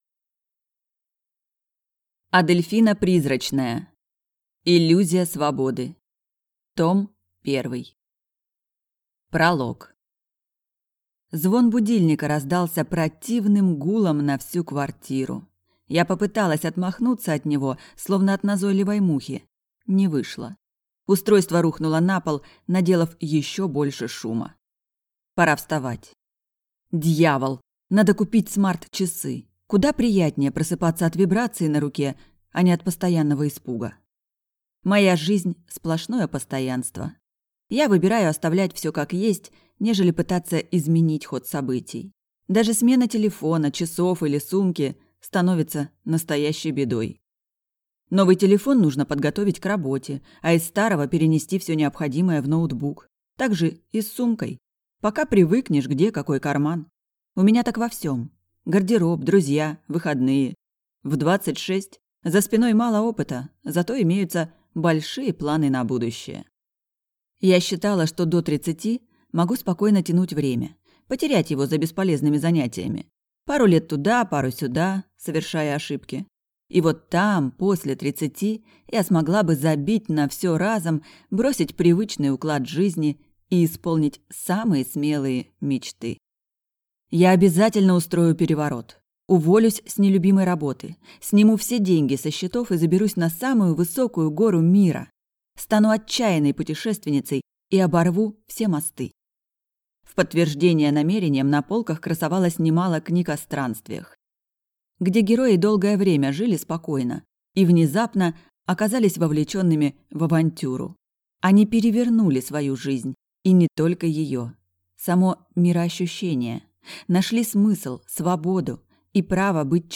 Аудиокнига Иллюзия Свободы. Том 1 | Библиотека аудиокниг